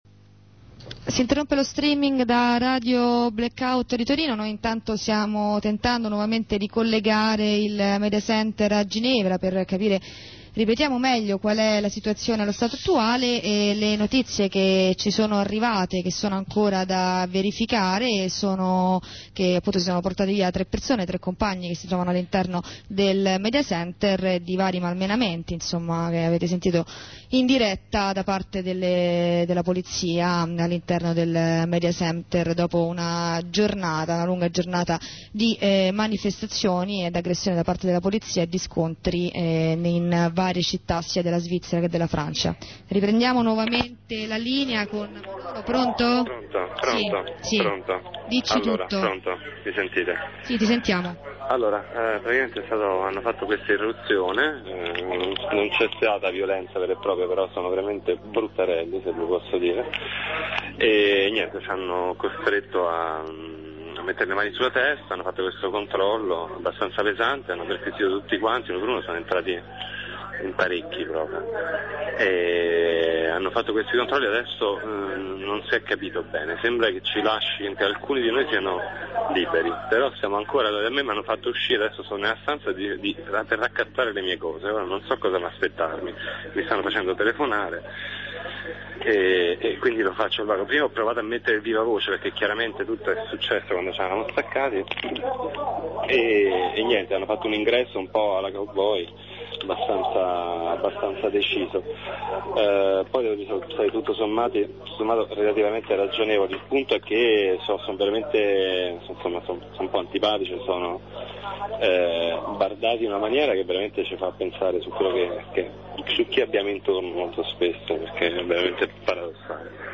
corrispondenza di Radio Onda Rossa con un mediattivista a Ginevra. 3'33''